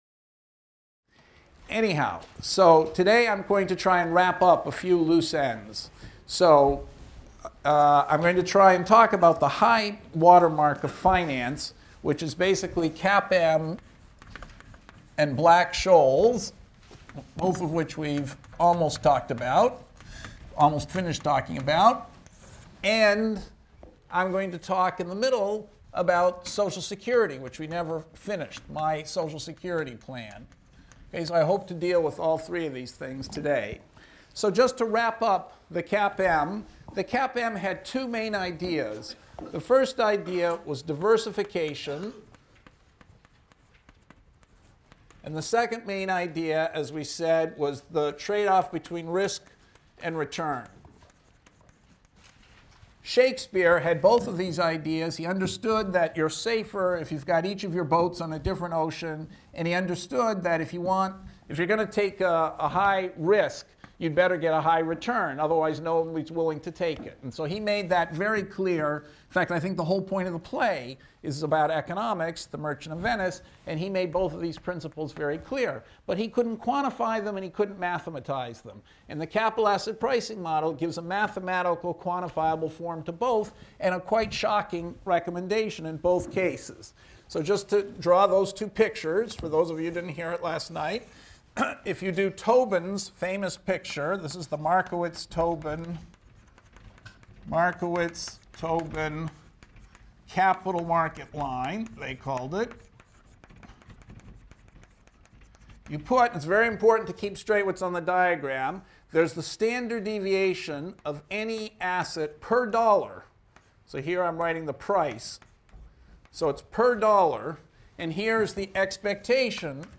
ECON 251 - Lecture 24 - Risk, Return, and Social Security | Open Yale Courses